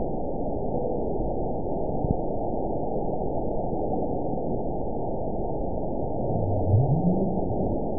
event 921702 date 12/16/24 time 22:46:30 GMT (10 months, 1 week ago) score 8.95 location TSS-AB04 detected by nrw target species NRW annotations +NRW Spectrogram: Frequency (kHz) vs. Time (s) audio not available .wav